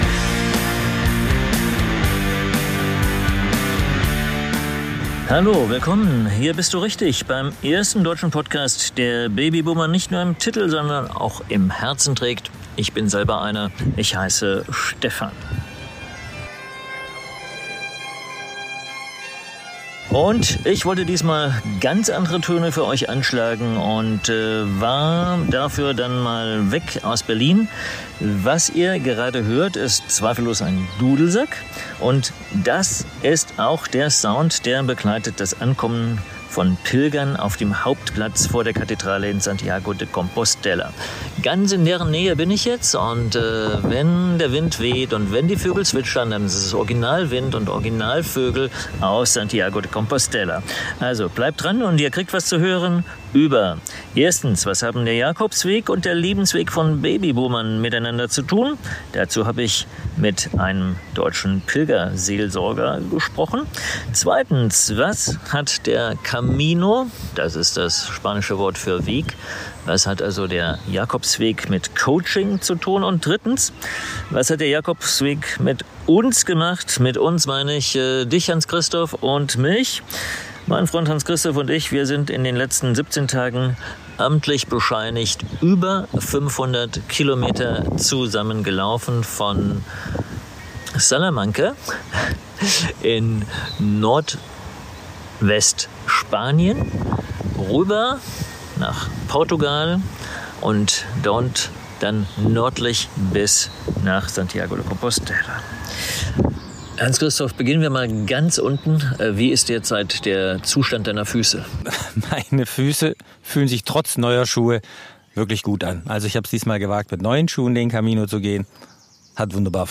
Was macht der Weg mit den Menschen? Was hat der Camino mit Coaching zu tun? Ps: kleines Gepäck, kleines Mikro, kleines Tonproblem.